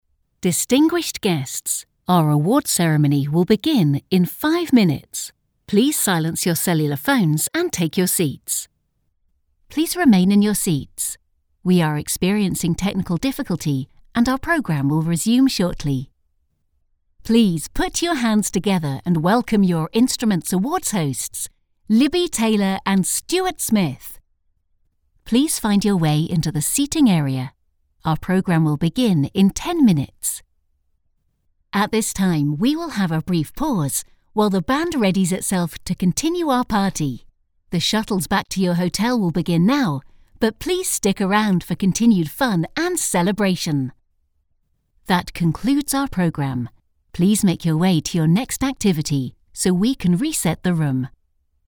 Announcements
I have my own studio where I record and edit to the highest professional standards, offering live direction where required.
Rode NT-1A microphone
Young Adult
Middle-Aged
Mezzo-SopranoSoprano